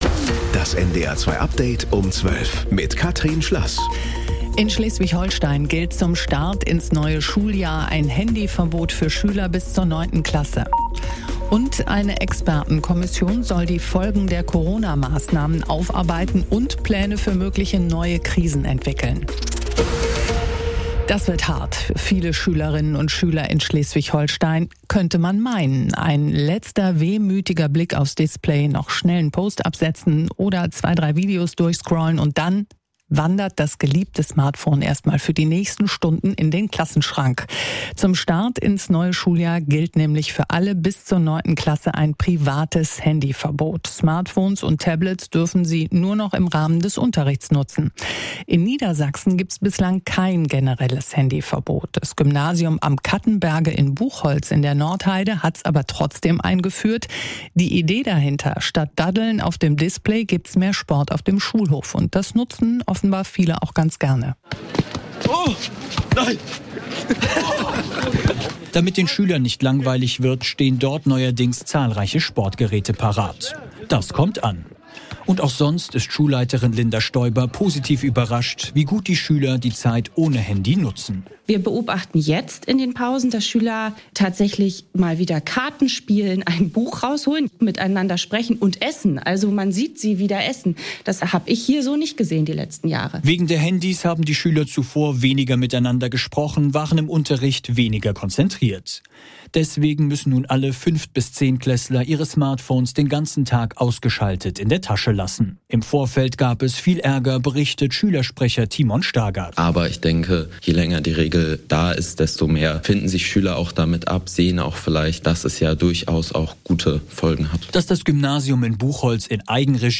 NDR 2 Tägliche Nachrichten Nachrichten NDR News Kurier Um 12 Update Um 12